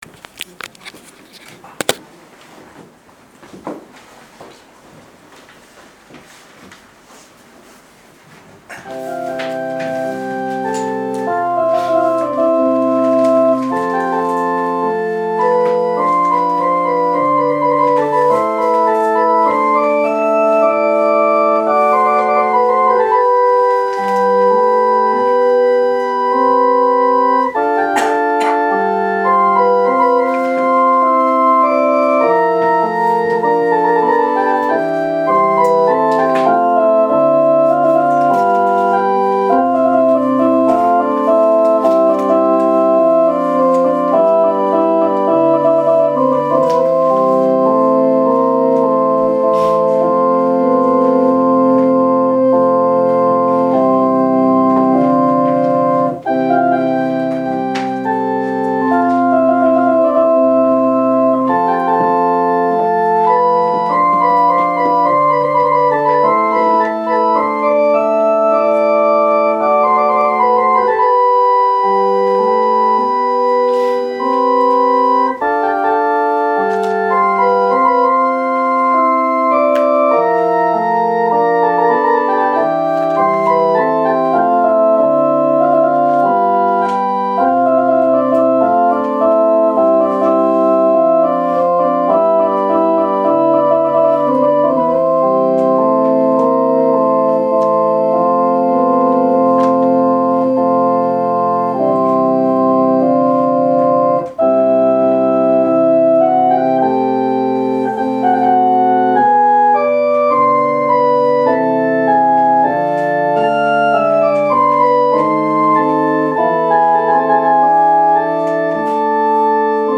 聖日礼拝のご案内（受難節第2主日） – 日本基督教団 花小金井教会